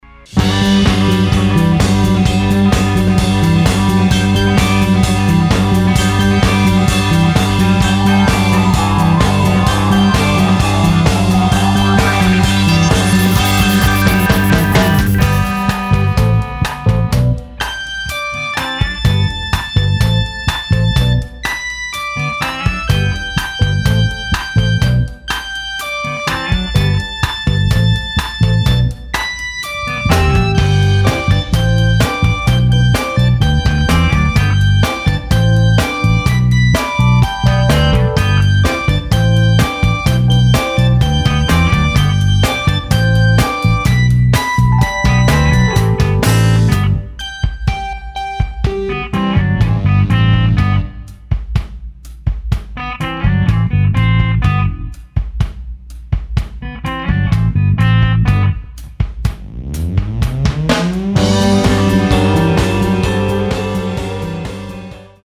唄と音の融合・広がり
ボーカル、アナログシンセ、ジャンベ
ギター＆コーラス
ベース＆コーラス
ドラムス
オルガン、ピアノ＆ボーカル